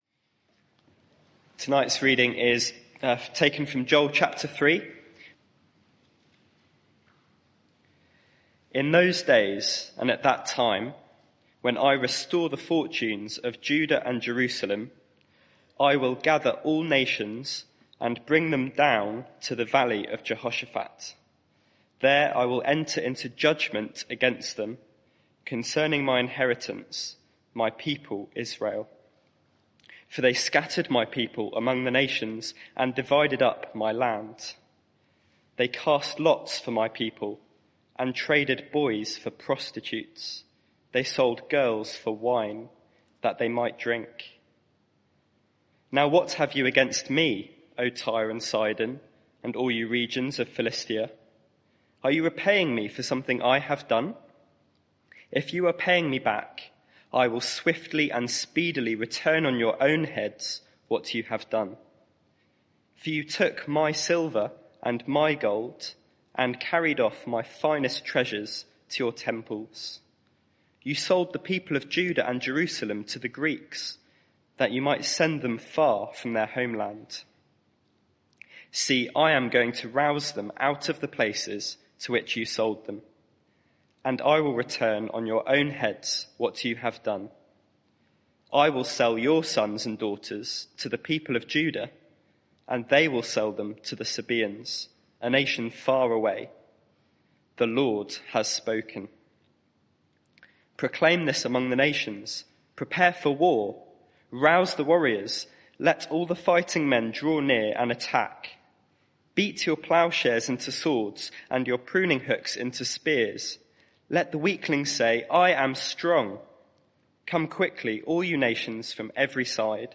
Media for 6:30pm Service on Sun 03rd Dec 2023 18:30 Speaker
Sermon (audio)